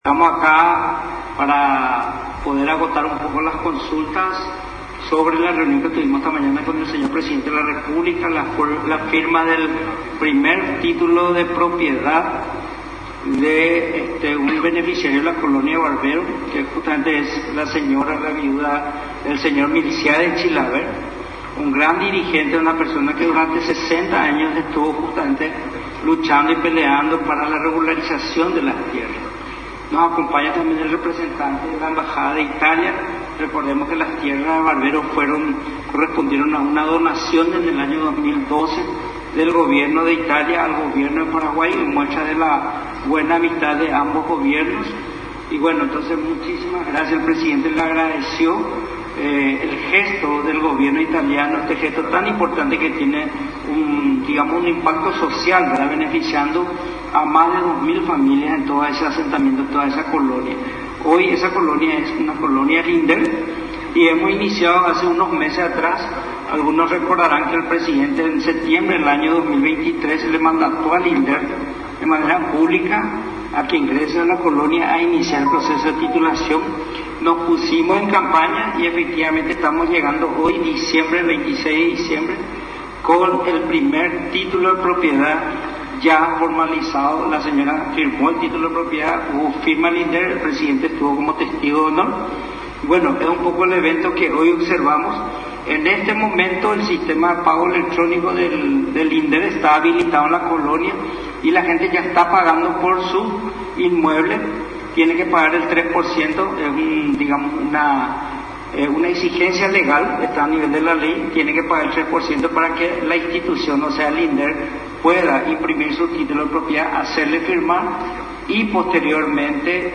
EDITADO-5-CONFERENCIA-DEL-INDERT.mp3